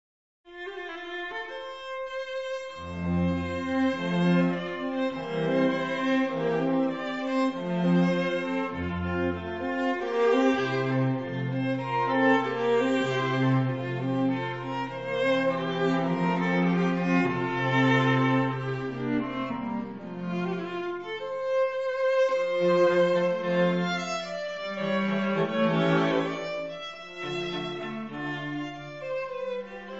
• quartetti
• registrazione sonora di musica